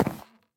sounds / step / wood2.ogg
wood2.ogg